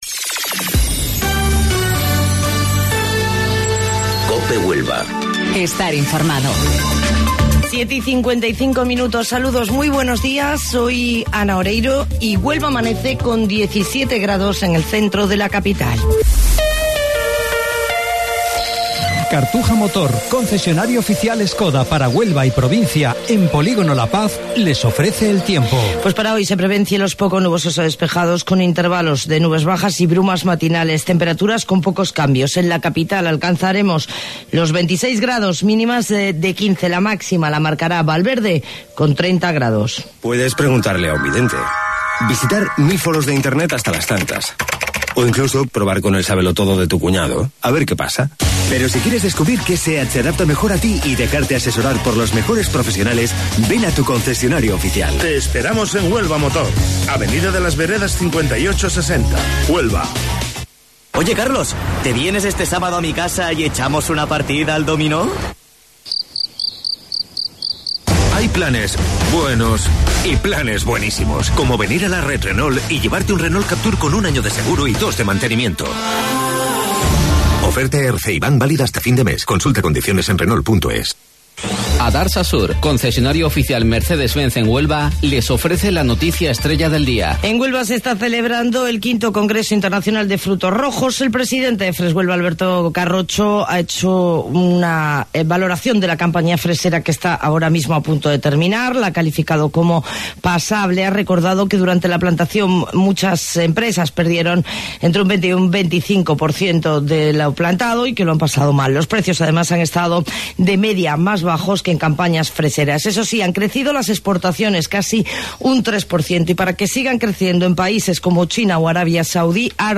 AUDIO: Informativo Local 07:55 del 20 de Junio